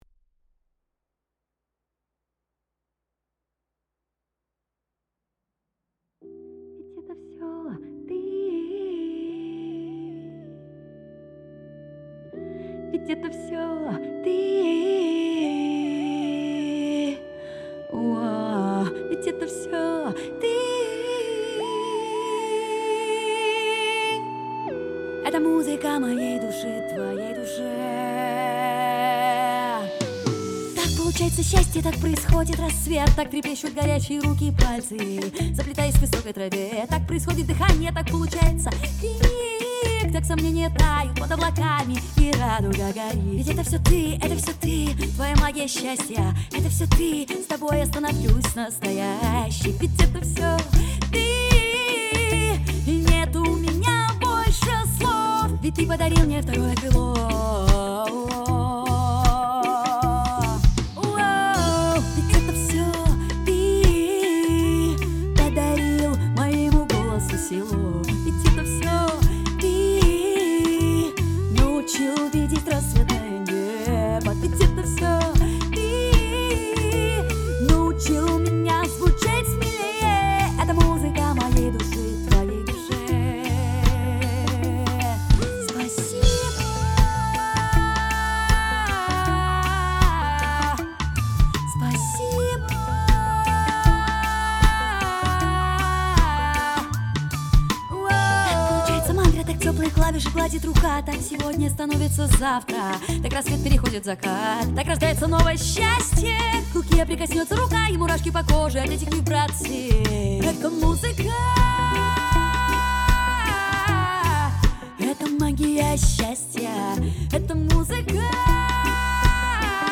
Он скинул мультитрек ребят которые у него писались. Проблема заключается в том, что уровень исполнения на инструментах далеко не идеал, поэтому пришлось обильно поработать ножницами.